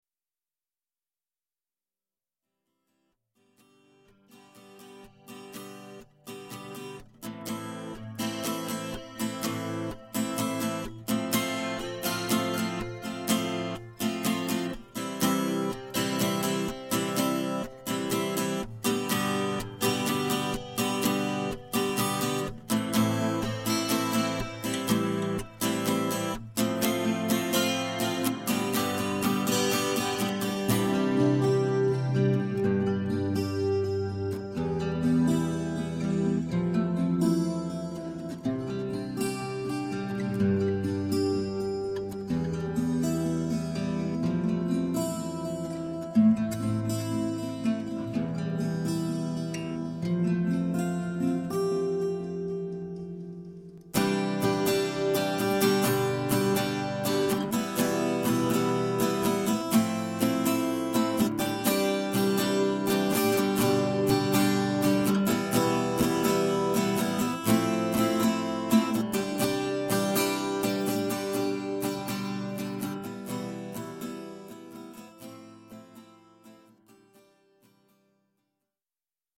A steel-string guitar
steel-string guitar